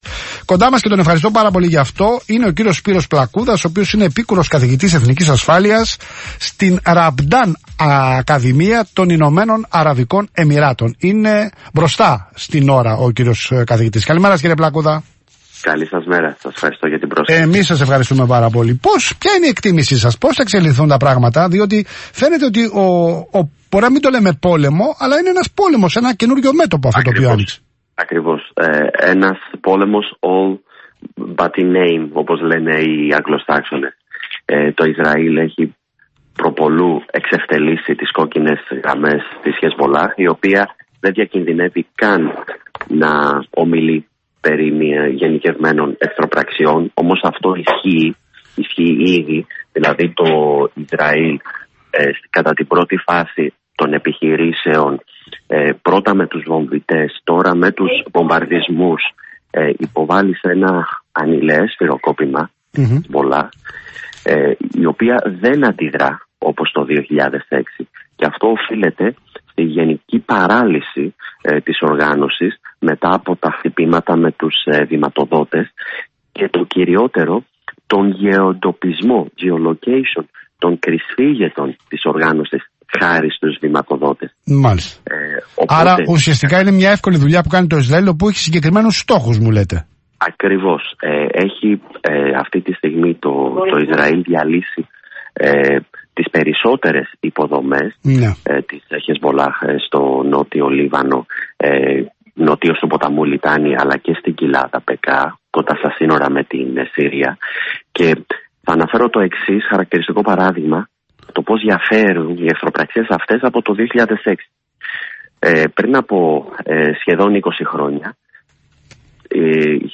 Ηχητικό απόσπασμα από τη συζήτηση στην εκπομπή "Πολιτικά" στον Ελλάδα FM περί Ελλάδας